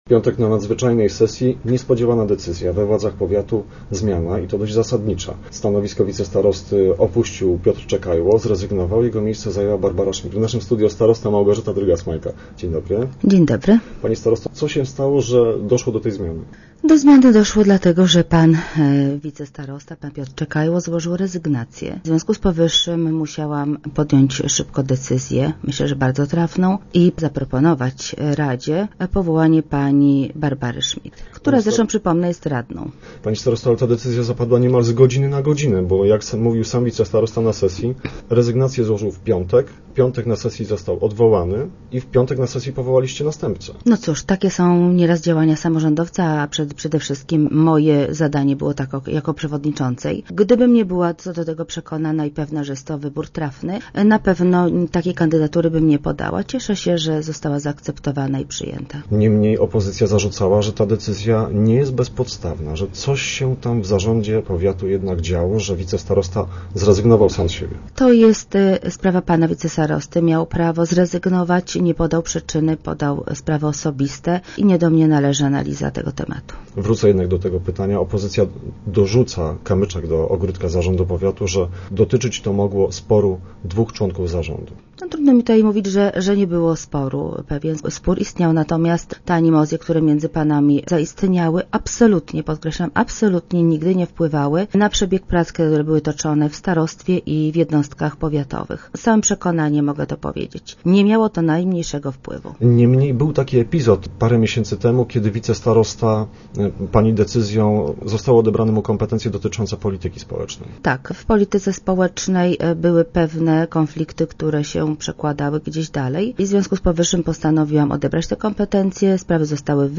Starosta w rozmowach Elki przekonuje że rezygnacja wicestarosty wymagała szybkiej reakcji.